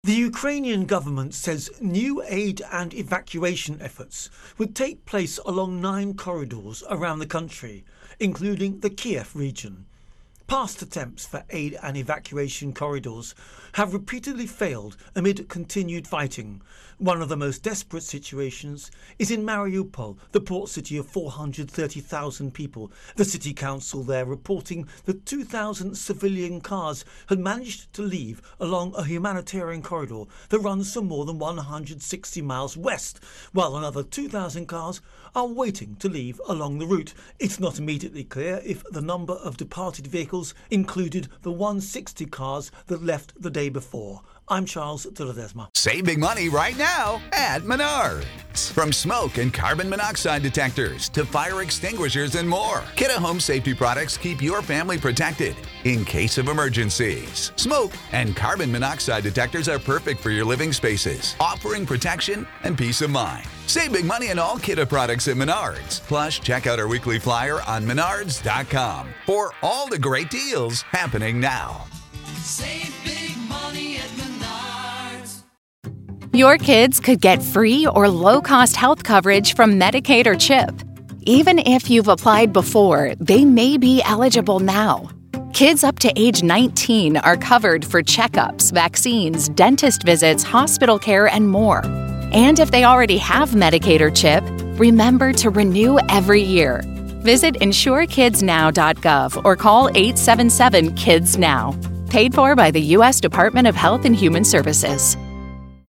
Russia-Ukraine-War-Evacuations Intro and Voicer